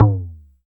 LOGTOM LO2P.wav